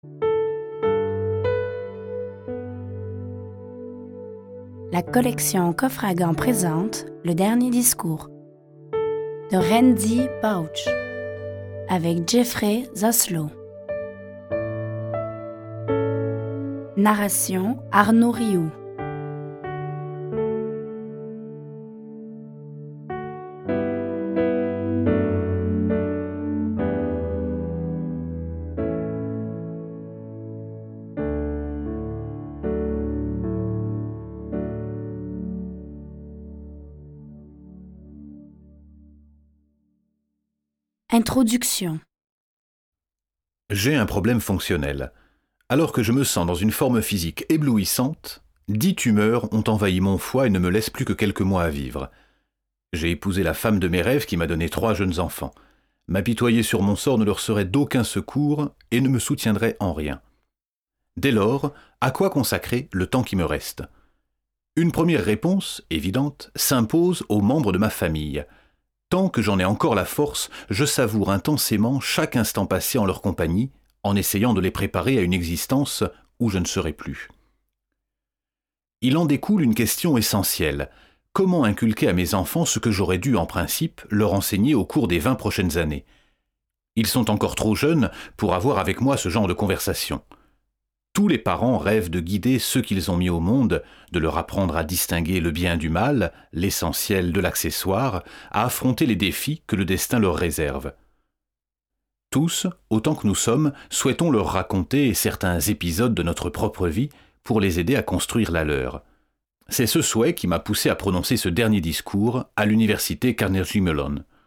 je découvre un extrait - Le dernier discours de Randy Pausch
Dans ce livre audio tiré de ses enseignements, Randy Pausch nous transmet un message courageux, humain, profondément philosophique, avec cette question qui devrait guider toute notre existence : « Si nous devions mourir demain, quel témoignage aimerions-nous laisser à